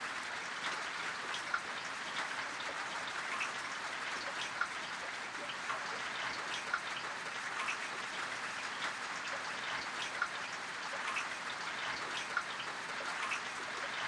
Synthesized examples twice the duration of the originals.
Heavy Rain original
HeavyRainmontSynTex.wav